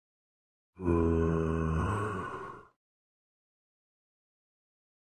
Minecraft Zombie Meme Sound Effect sound effects free download